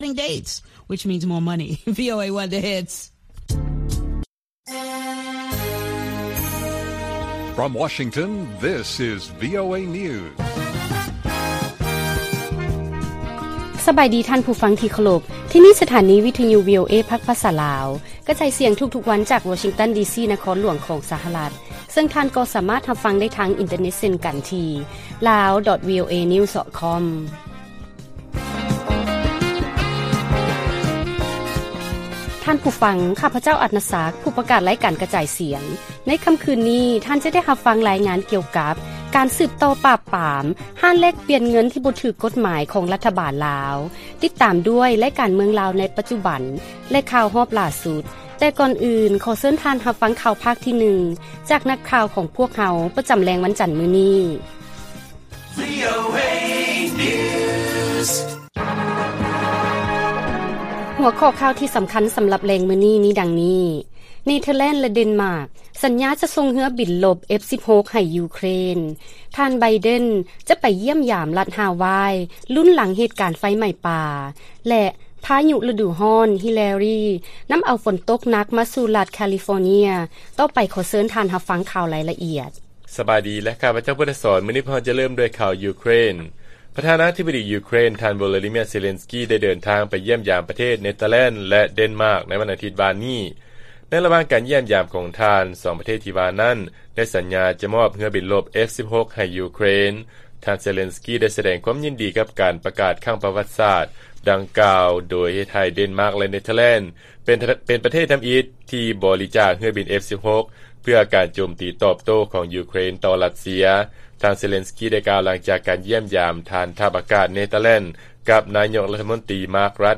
ລາຍການກະຈາຍສຽງຂອງວີໂອເອ ລາວ: ເນເທີແລນ ແລະ ເດັນມາກ ສັນຍາຈະສົ່ງເຮືອບິນລົບ F-16 ໃຫ້ ຢູເຄຣນ